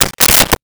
Switchboard Telephone Receiver Down 03
Switchboard Telephone Receiver Down 03.wav